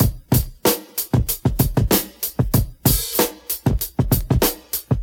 95 Bpm 1970s R&B Drum Loop Sample A# Key.wav
Free breakbeat sample - kick tuned to the A# note. Loudest frequency: 5181Hz
.WAV .MP3 .OGG 0:00 / 0:05 Type Wav Duration 0:05 Size 868,16 KB Samplerate 44100 Hz Bitdepth 16 Channels Stereo Free breakbeat sample - kick tuned to the A# note.